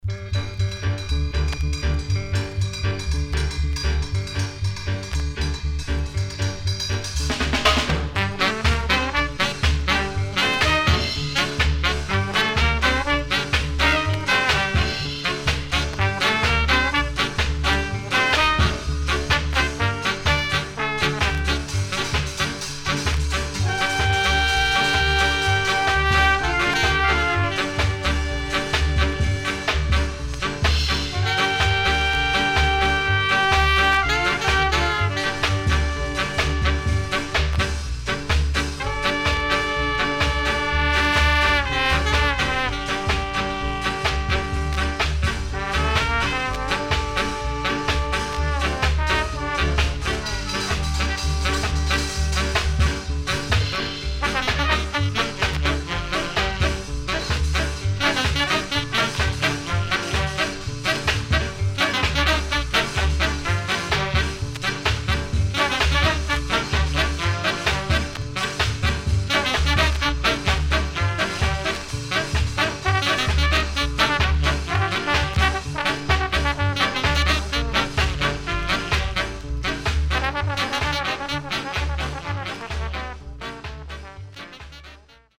SIDE B:プレス起因で所々ノイズ入りますが良好です。